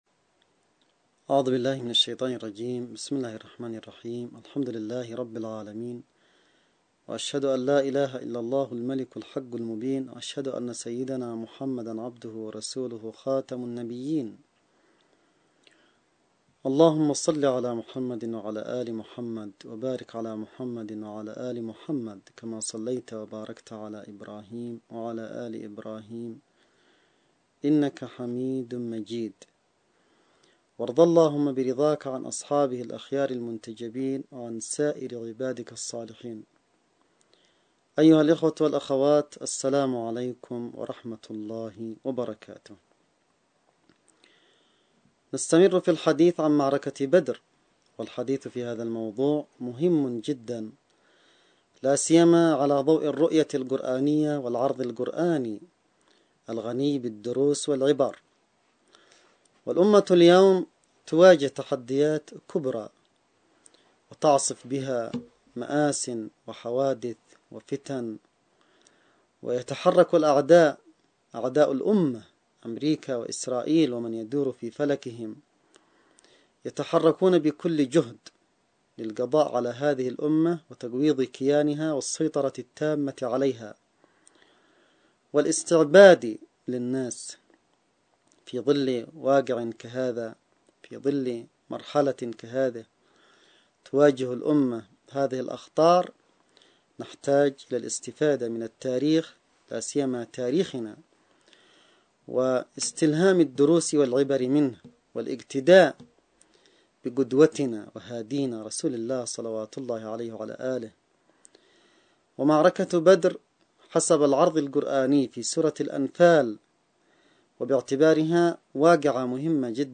(نص+فيديو+أستماع) لـ محاضرة دروس من معركة بدر الكبرى – المحاضرة الرمضانية السادسة عشرة للسيد عبدالملك بدرالدين الحوثي 1439 هـ .